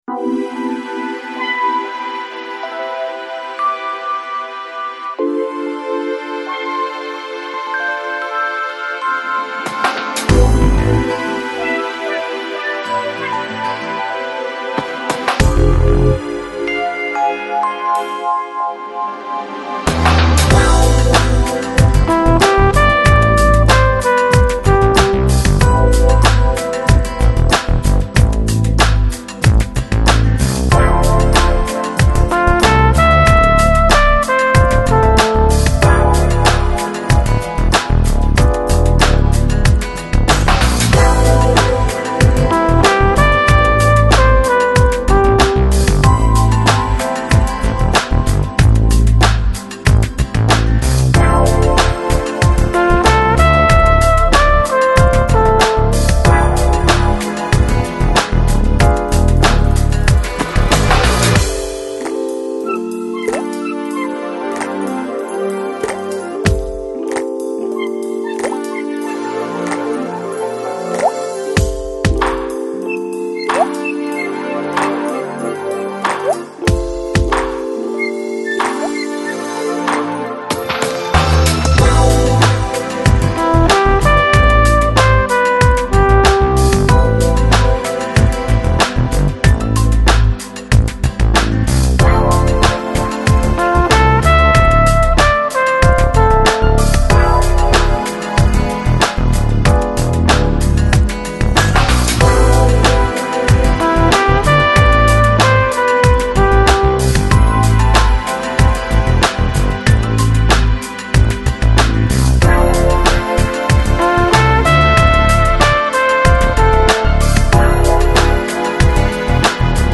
Жанр: Electronic, Easy Listening, Lounge, Nu Jazz